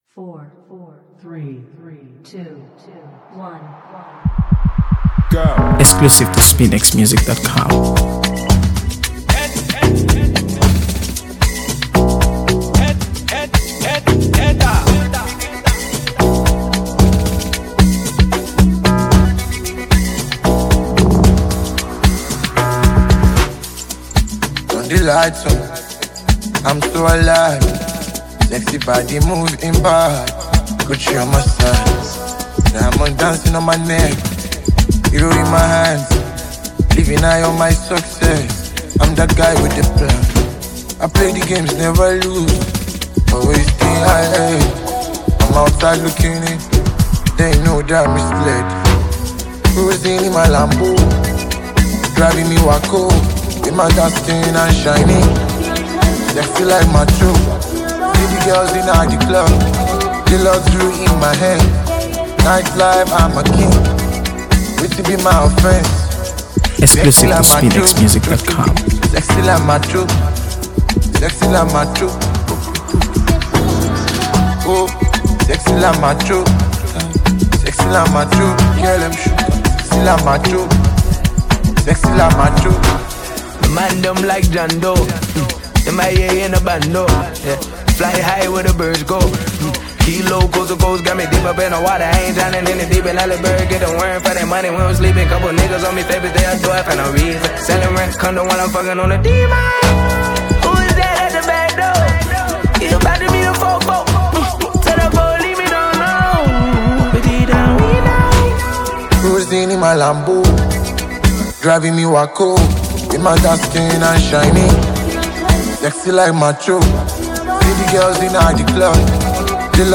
AfroBeats | AfroBeats songs
With its infectious melody and hard-hitting verses